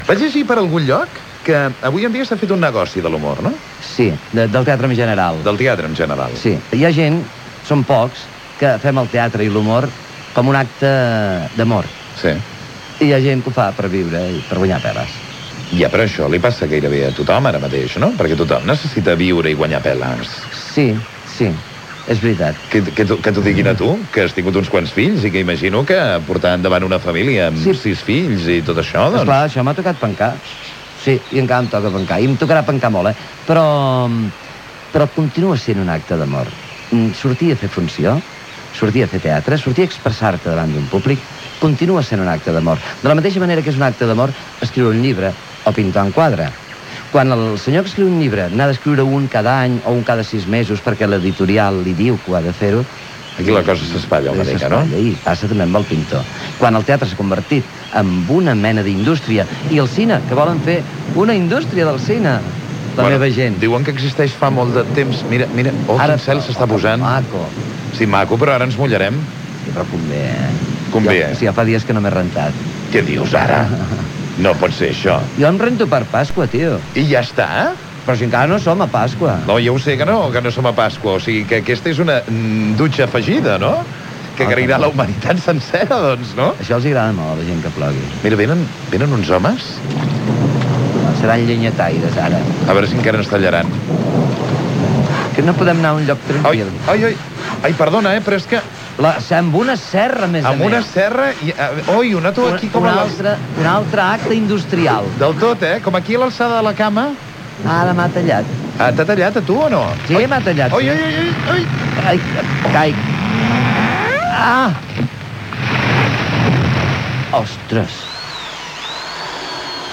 Entrevista al'actor Joan Pera. El que significa fer teatre, la pluja i el tall d'un arbre
Entreteniment
FM